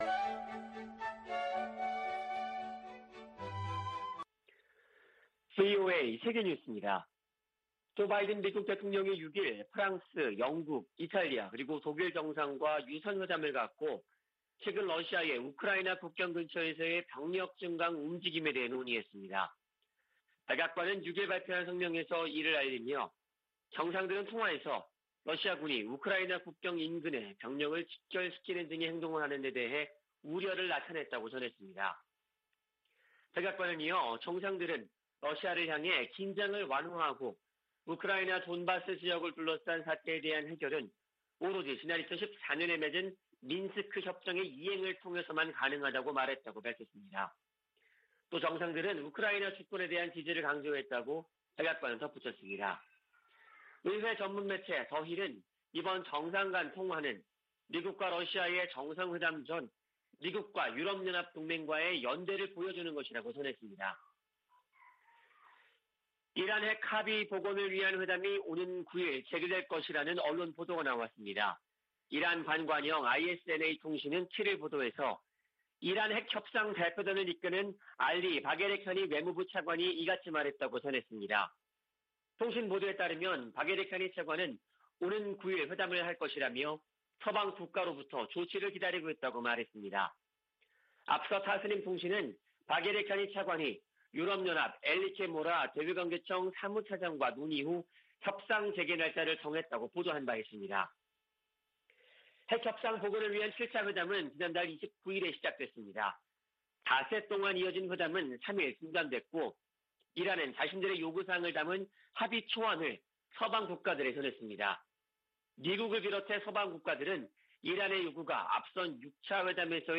VOA 한국어 아침 뉴스 프로그램 '워싱턴 뉴스 광장' 2021년 12월 8일 방송입니다. 미국이 중국의 인권 탄압을 이유로 내년 2월 베이징 동계올림픽에 정부 공식대표단을 파견하지 않는다고 공식 발표했습니다. 유럽연합(EU)이 북한인 2명과 기관 1곳 등에 인권제재를 1년 연장했습니다. 조 바이든 미국 대통령이 오는 9일과 10일 한국과 일본 등 전 세계 110개국이 참가하는 민주주의 정상회의를 화상으로 개최합니다.